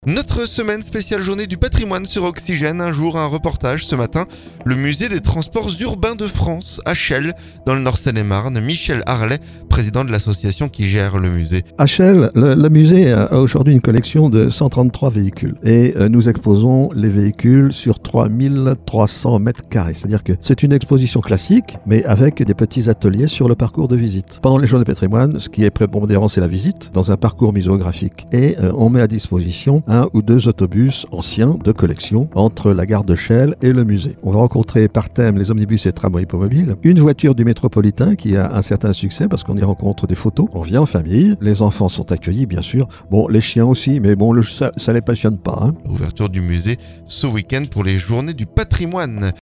Un jour, un reportage. Ce mardi, le Musée des transports urbain de France, à Chelles, dans le nord Seine-et-Marne.